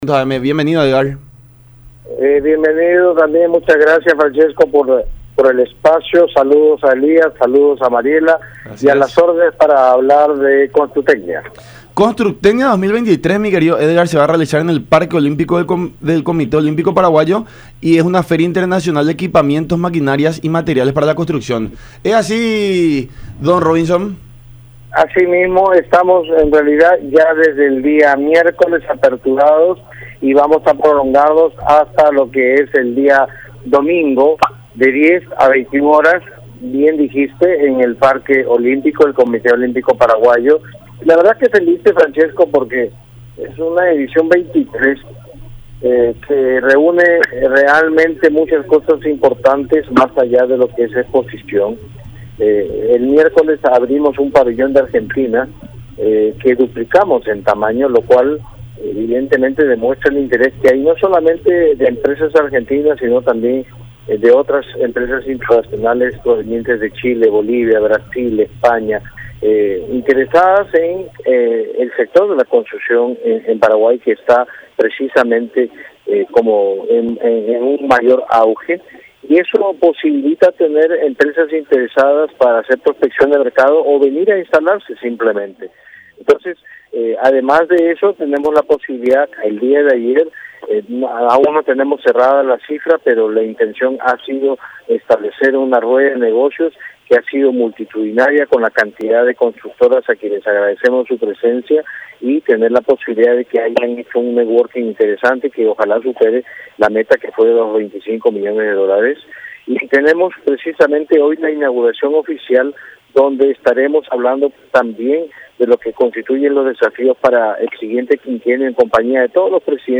en diálogo con La Unión Hace La Fuerza a través de Unión TV y radio La Unión.